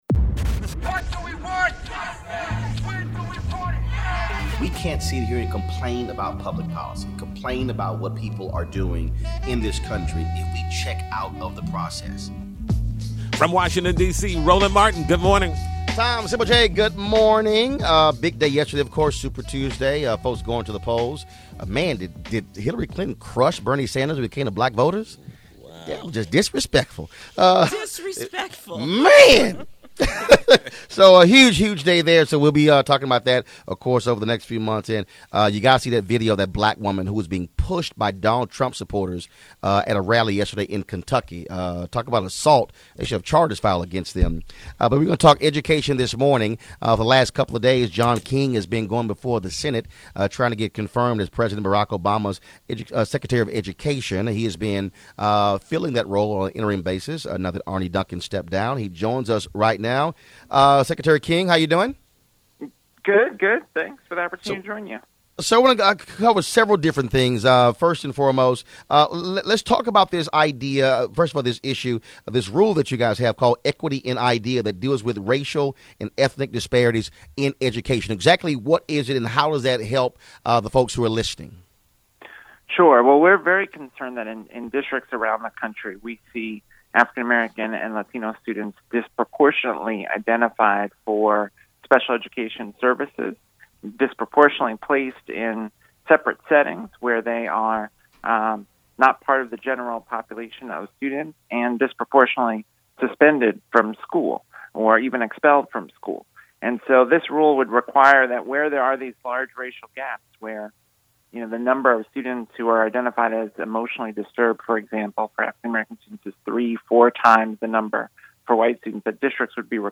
Roland Martin talks to interim Secretary of Education John King about the rule that deals with racial inequities that plague students of color in this country.